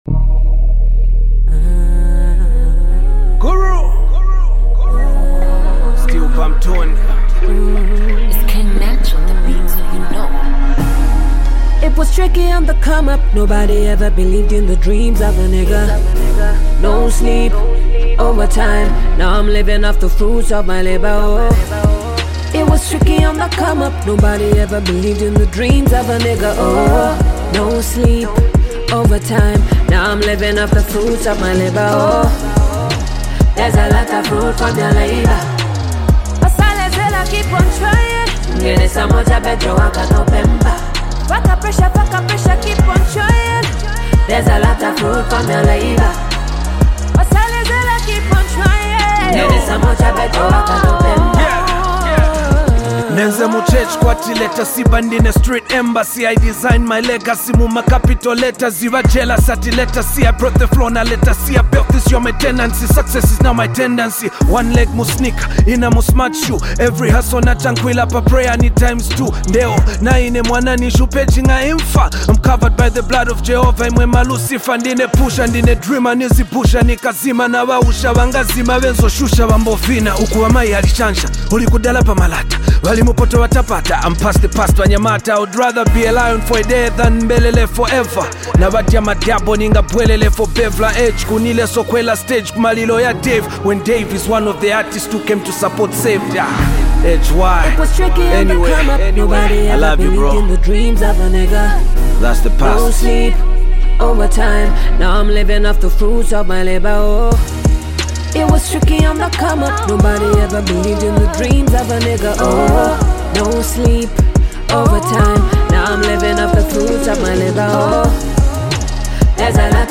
powerful vocals add an empowering touch to the track
an inspiring anthem for dreamers and hustlers alike